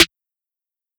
Polow Snare.wav